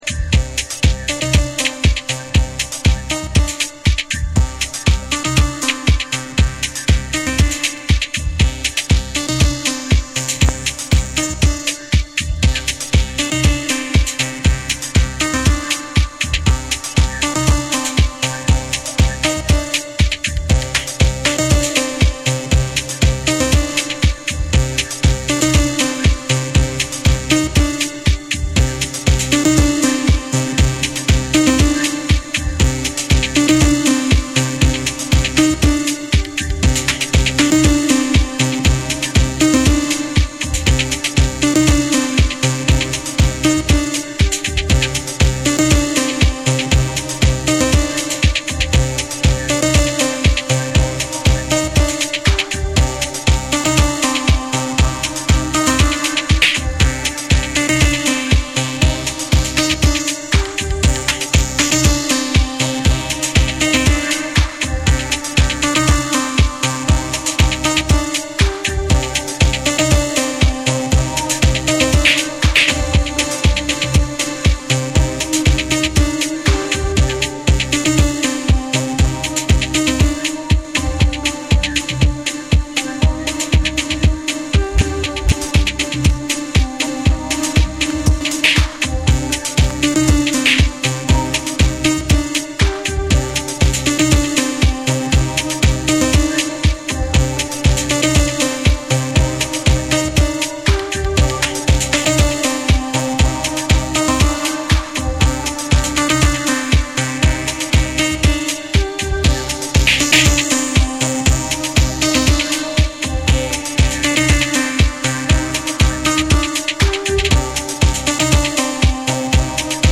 TECHNO & HOUSE